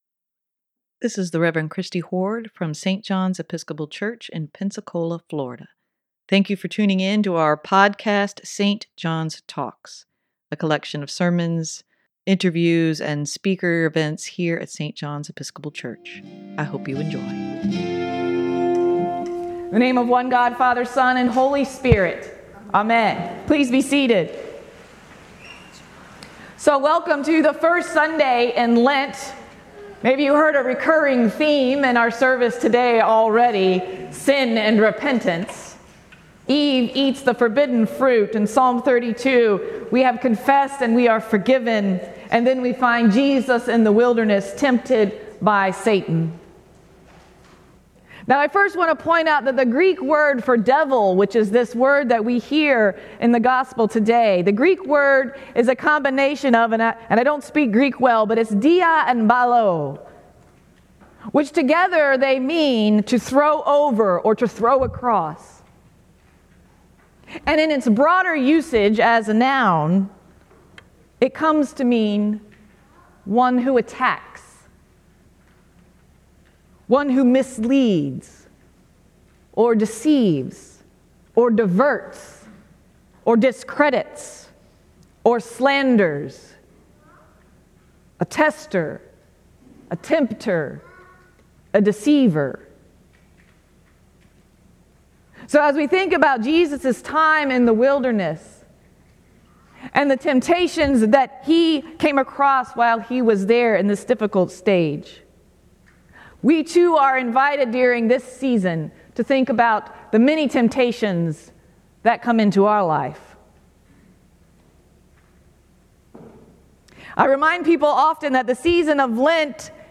sermon-2-26-23.mp3